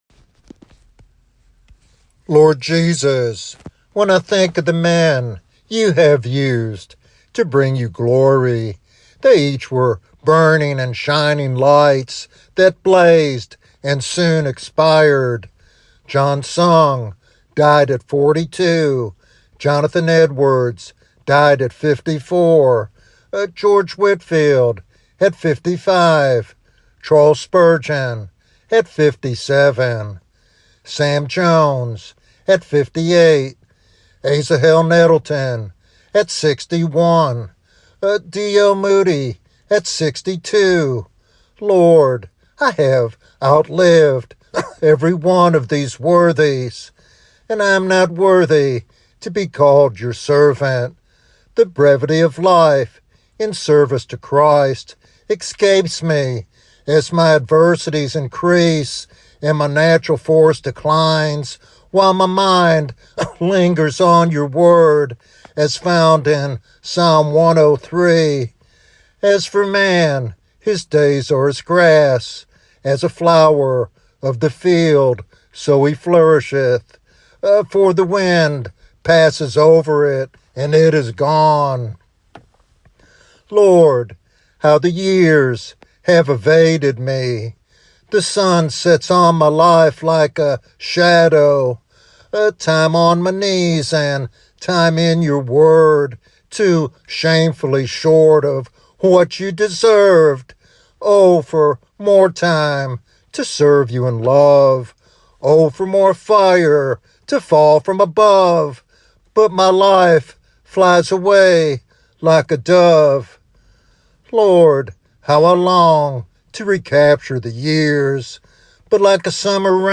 What is the main theme of the sermon?